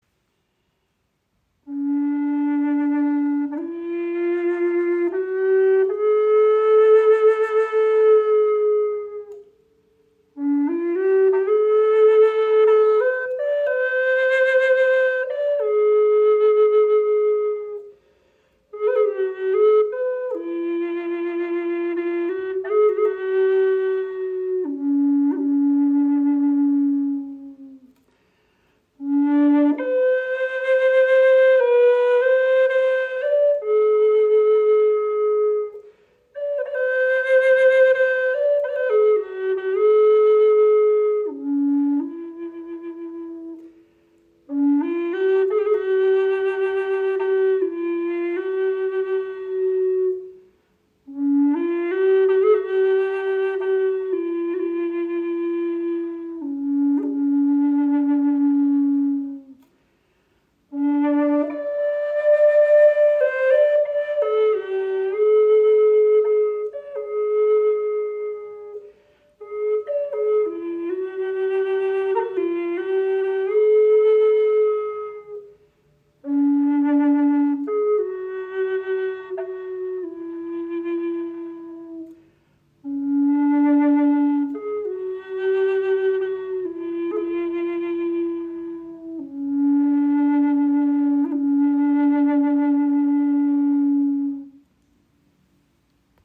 Gebetsflöte in D-Moll | Bärenklauen-Windblock | Olivenholz | Woodsounds
• Icon Länge 69 cm, 3 cm Innenbohrung – klarer, warmer Klang
Die Windpony Concerto Low D ist eine klangvolle Flöte in tiefer D Stimmung. Ihr Ton entfaltet sich warm, klar und tragend, mit einer Resonanz, die sich sanft modulieren lässt.
Mit einem Innendurchmesser von 3 cm entwickelt die Flöte ein resonanzreiches Klangbild.